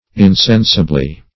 insensibly - definition of insensibly - synonyms, pronunciation, spelling from Free Dictionary
Insensibly \In*sen"si*bly\, adv.